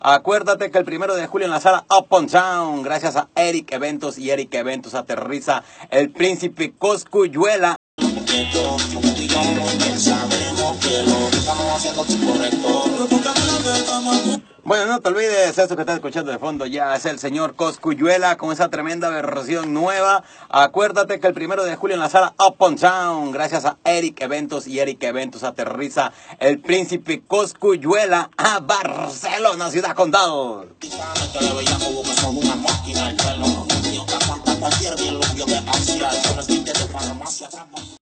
Anunci de l'actuació de El Príncipe Cosculluela a la sala Up & Down de Barcelona